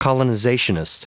Prononciation du mot colonizationist en anglais (fichier audio)
Prononciation du mot : colonizationist